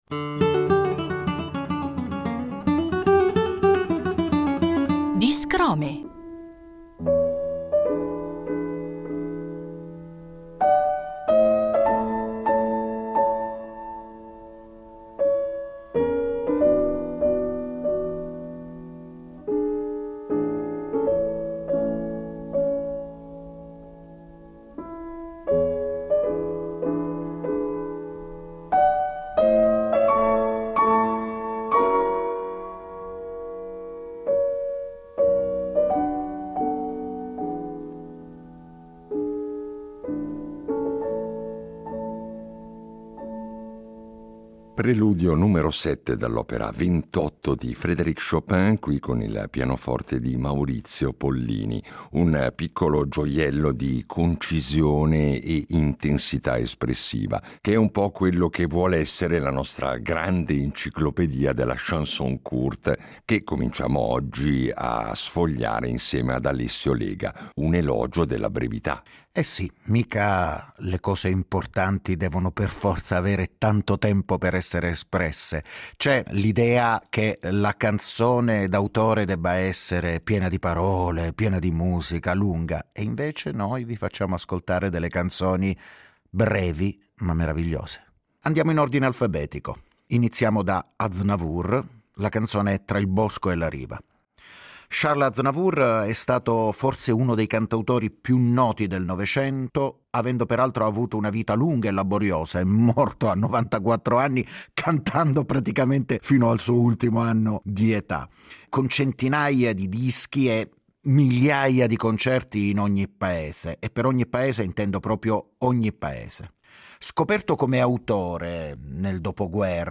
Vi facciamo ascoltare queste canzoni, in versione discografica o live, raccontandovi qualcosa dei loro autori e del contesto in cui nacquero… poesia, musica e storie in cinque minuti: un buon affare!